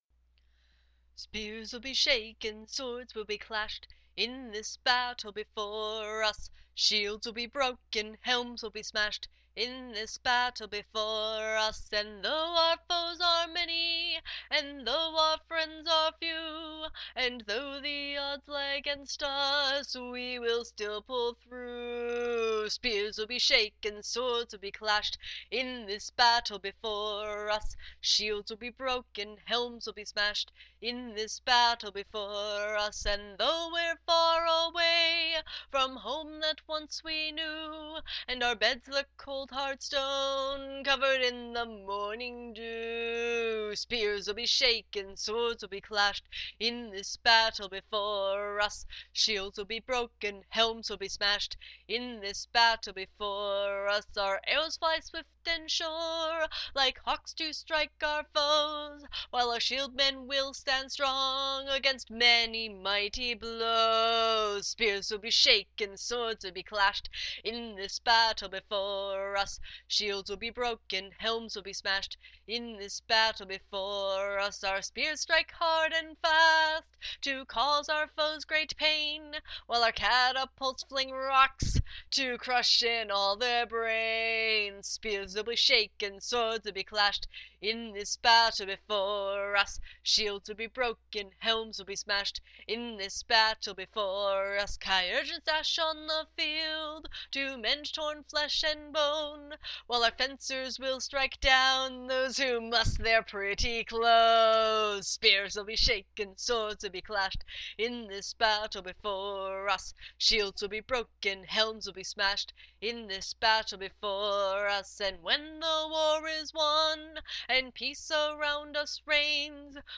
Several years ago, I was commissioned to write a war song for a nation called Chimeron in a LARP.
Chimeron-War-Song-Rough-Cut.mp3